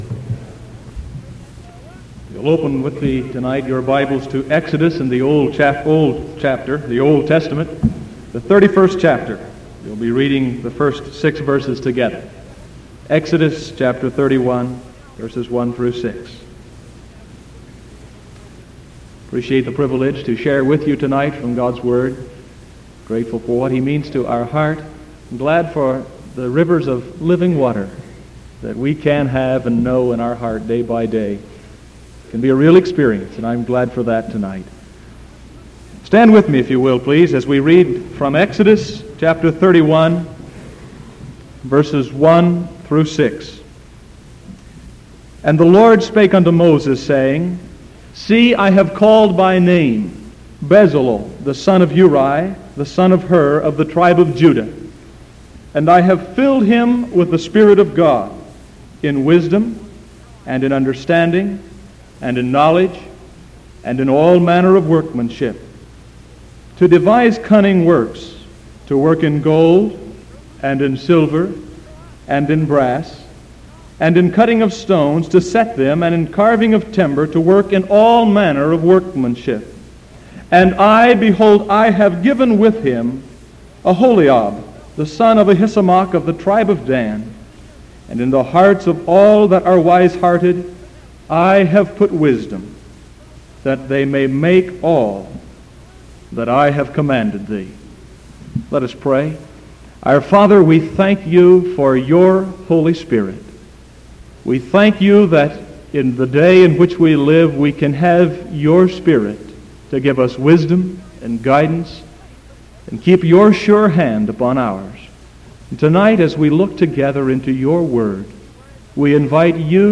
Sermon December 30th 1973 PM